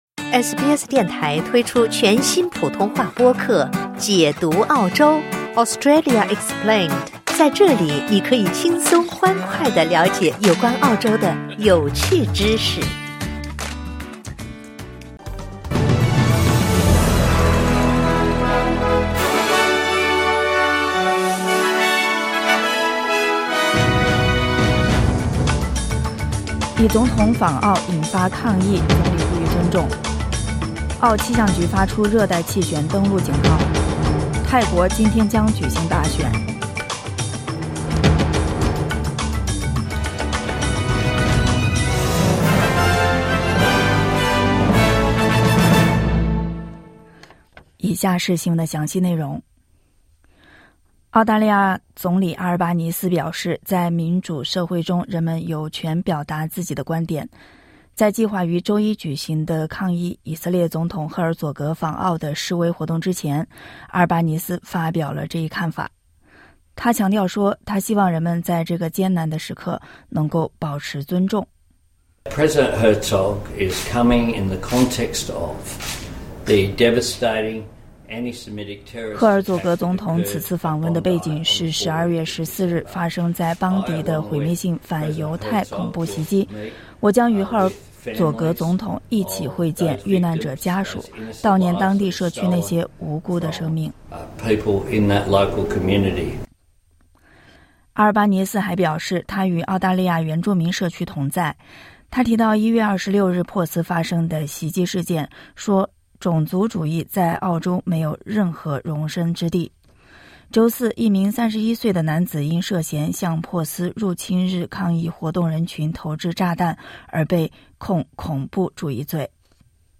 SBS Mandarin morning news.